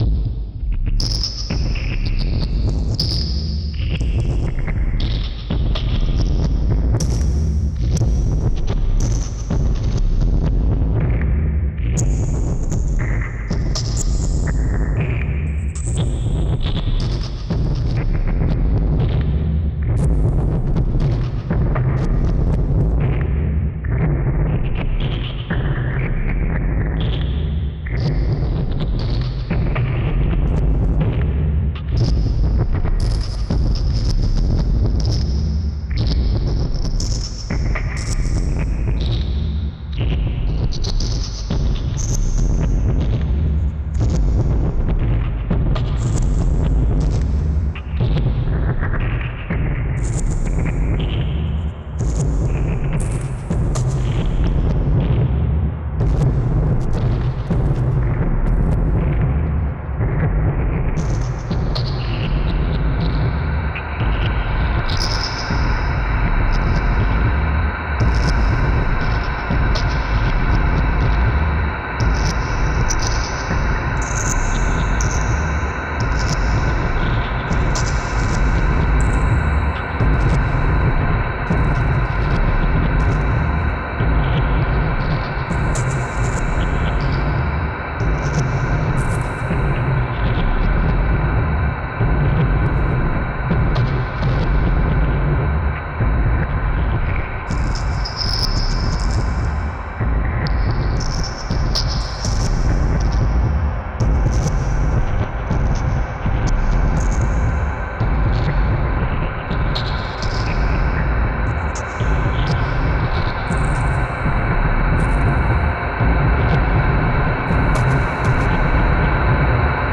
本作は多数のギターの重ね取りと、コンピュータによって作られました。
複雑緻密なパンニングと周波数合成を含みますので、良質のヘッドホンでの御愛聴をお勧めします。